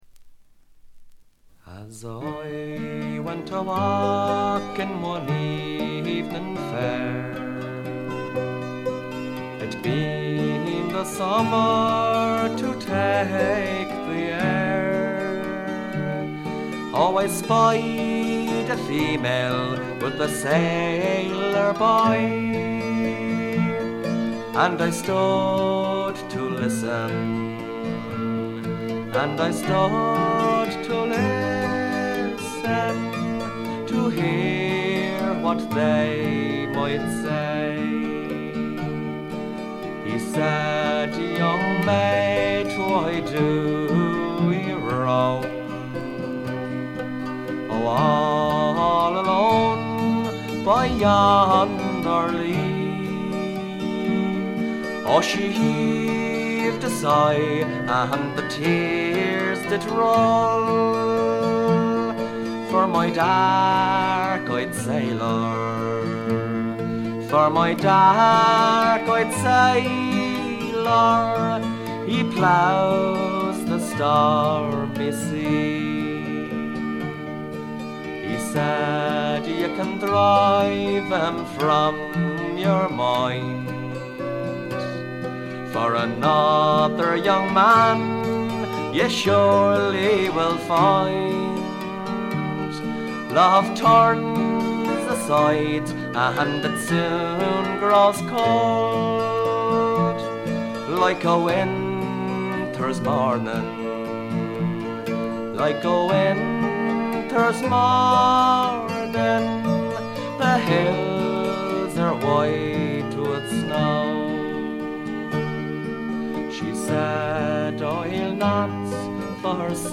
ほとんどノイズ感無し。
アイリッシュ・フォーク基本中の基本です。
中身は哀切なヴォイスが切々と迫る名盤。
試聴曲は現品からの取り込み音源です。
fiddle
concertina
bodhran